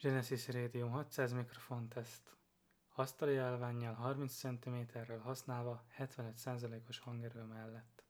Hangminőség teszt: csendes környezet
Hogy hallható legyen a zaj, amit összeszed, kipróbálásra került csendes környezetben is.